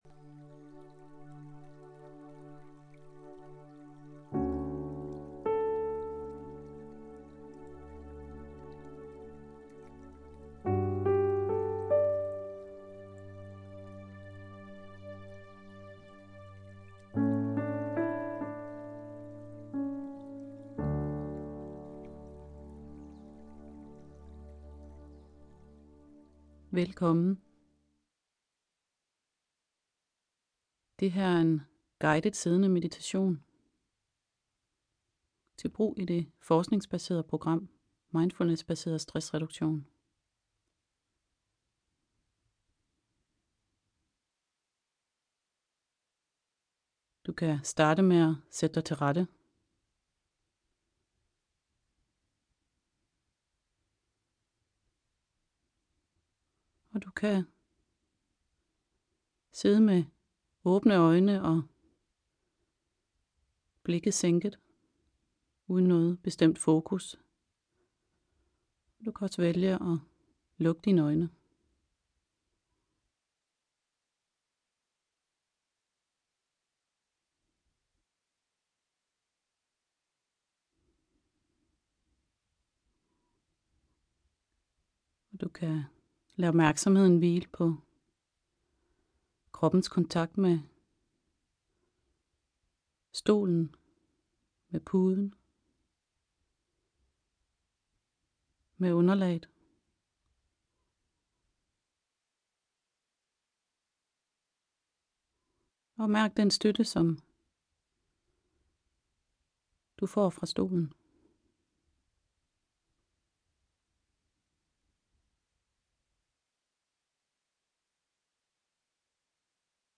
Guidet siddende meditation